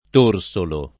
torsolo [ t 1 r S olo ]